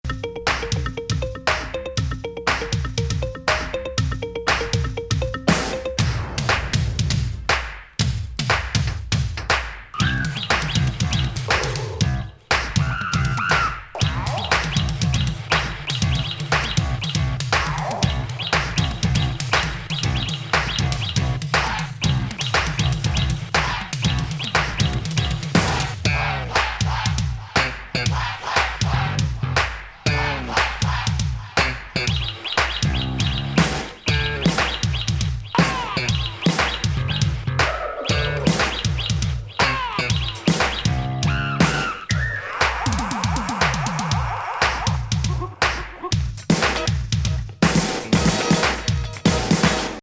イタリー産シンセ・ビッキビキ・ハイエナジー・エレクトリック・ブギー
ロッキン・イタロ・ディスコ！